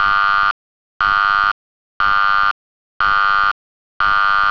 ZUMBADOR - SONIDO INTERMITENTE
Zumbador electromagnético de fijación base/mural
Sonido intermitente
92dB